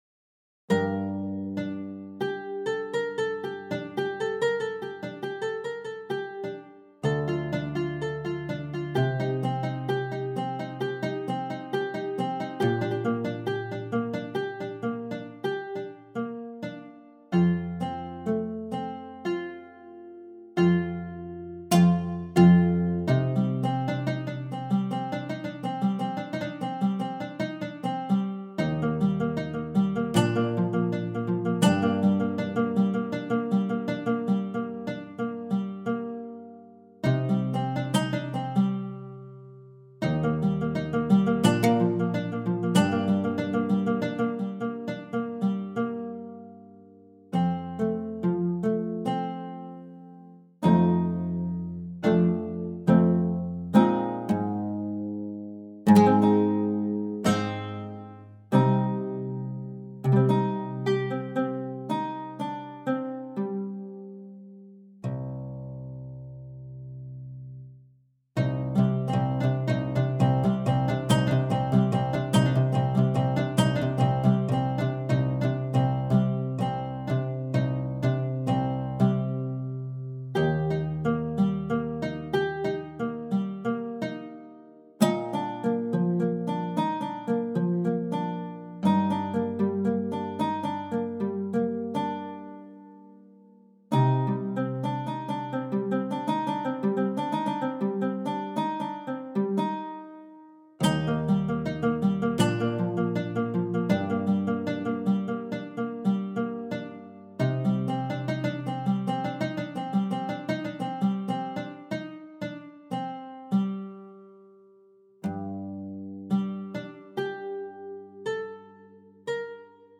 Musique - Le Oud
J'adore la chaleur de cet instrument qui est capable de sortir des sonorités vraiment exceptionnelles !Sans prétention, voila une petite impro perso que j'ai enregistré le week-end dernier.
Sans prétention, voila une petite impro perso que j'ai enregistré le week-end dernier. Ce n'est qu'une impro., avec des ratés de débutant mais je vais la bosser pour en faire quelque chose ! http